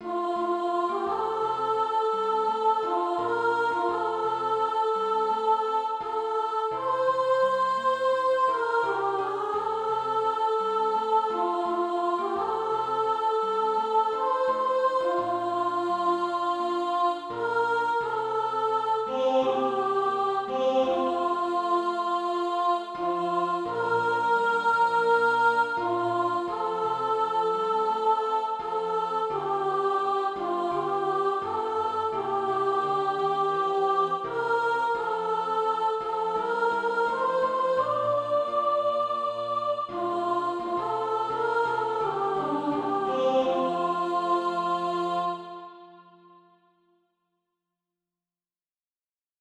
Soprano Track.
Practice then with the Chord quietly in the background.